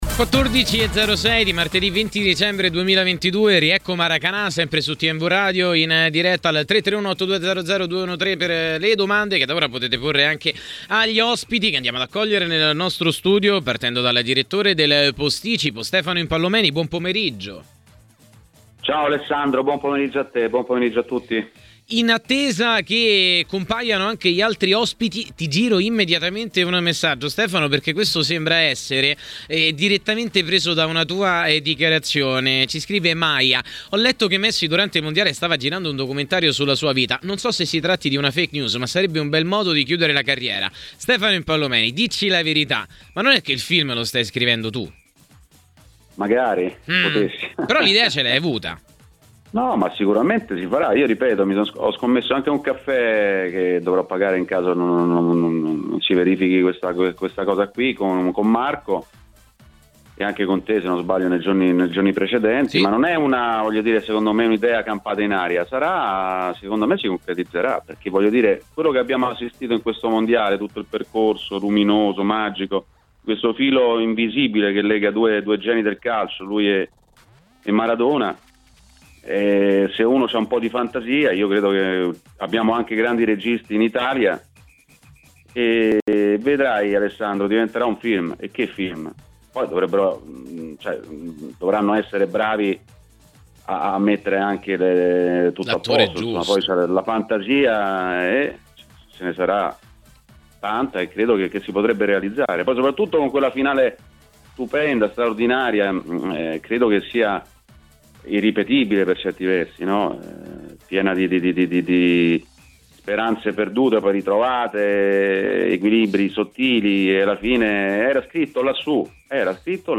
è intervenuto a Maracanà, trasmissione del pomeriggio di TMW Radio.